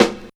81 SNARE 5.wav